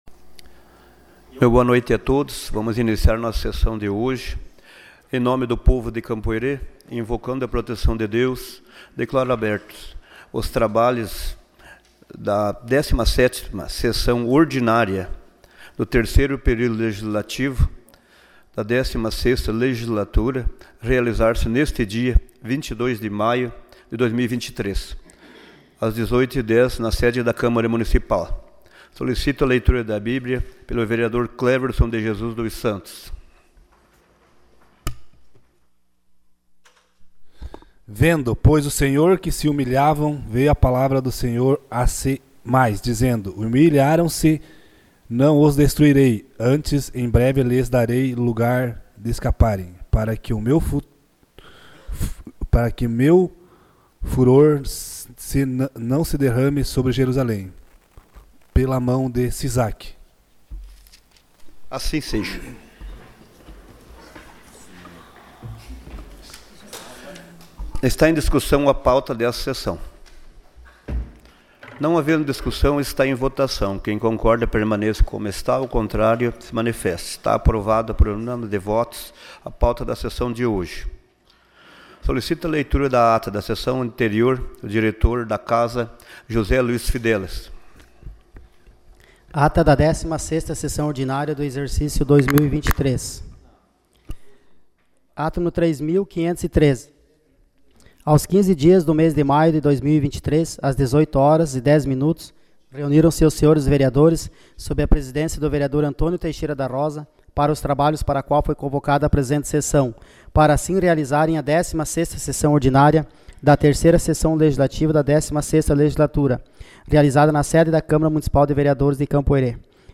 Sessão Ordinária dia 22 de maio